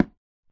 dig / wood2